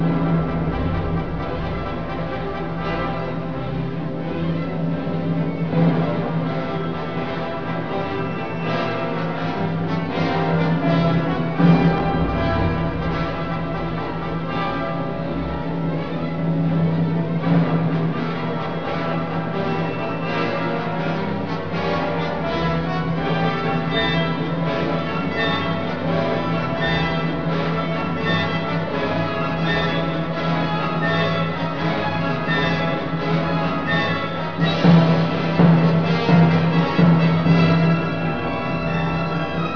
Rapide di San Giovanni: tutta l'orchestra è impegnata in un crescendo, fino a evocare il fragore dell'acqua che precipita gorgogliando fra le rocce.